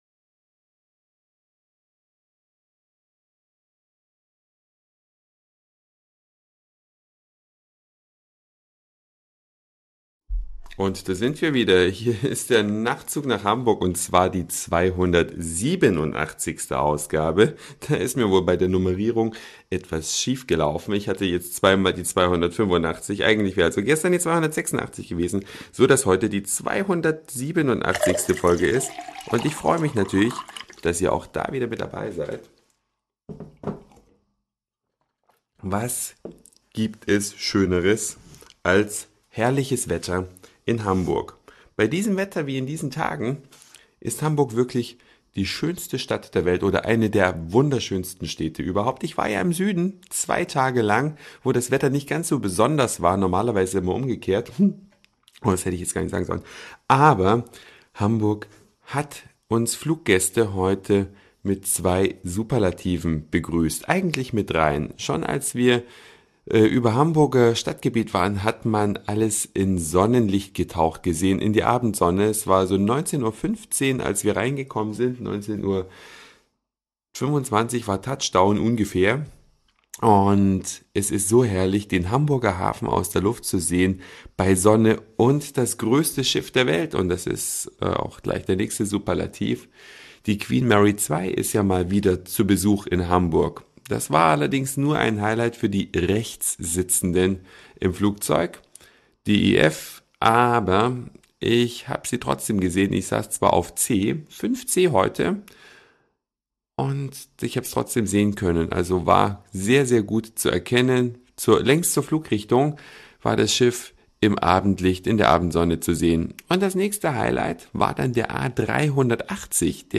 3 Superlative beim Landeanflug auf Hamburg am heutigen Abend.